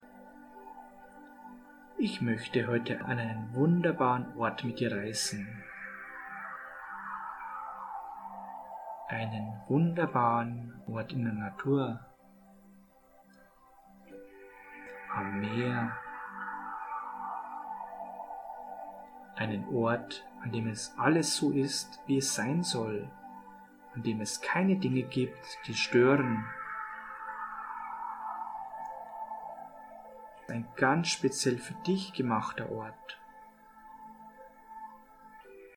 Unsere geführte Hypnose führt Sie an einen wunderschönen Strand, an welchem Sie Ihre Seele baumeln lassen können.